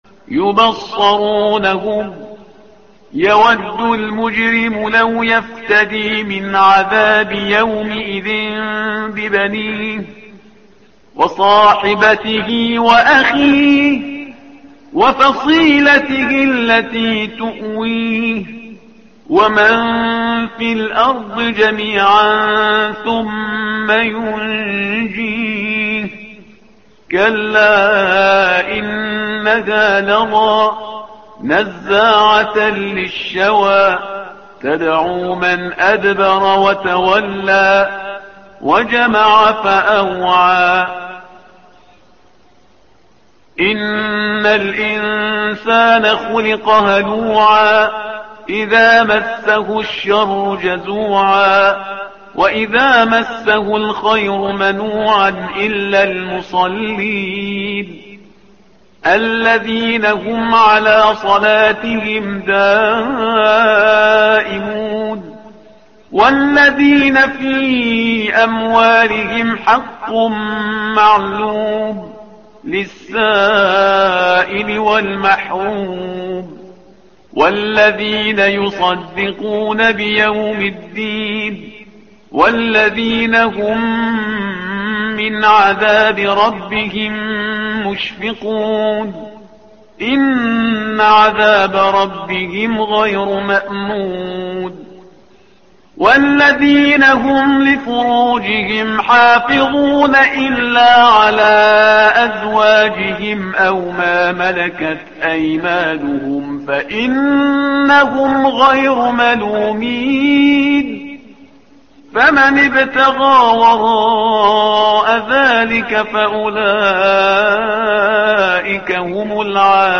الصفحة رقم 569 / القارئ